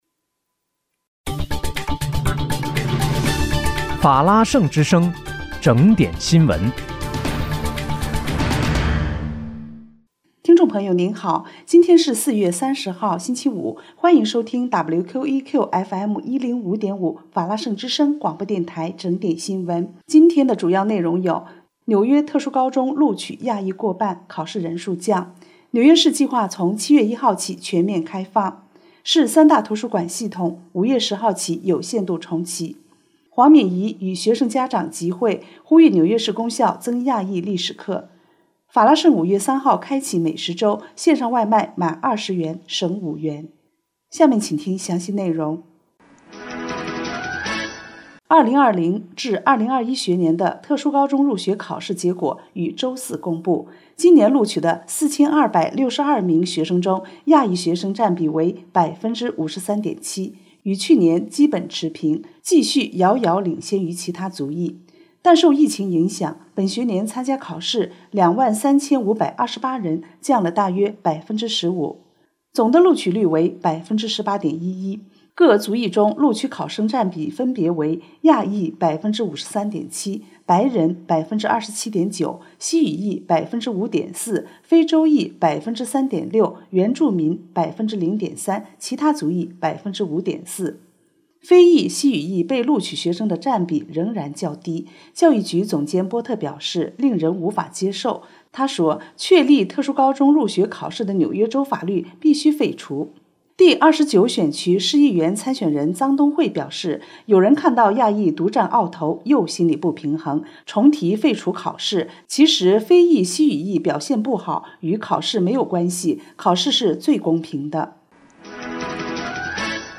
4月30日（星期五）纽约整点新闻